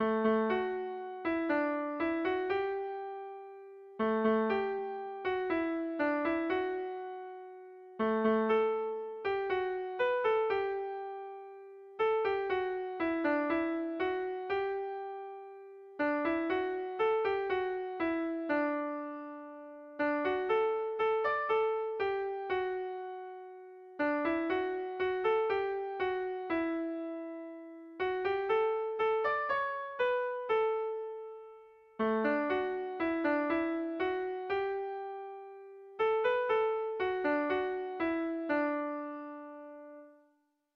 Gabonak datoz Gabonak - Air de bertsos - BDB.
Gabonetakoa
ABD....